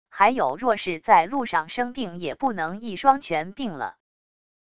还有若是在路上生病也不能一双全病了wav音频生成系统WAV Audio Player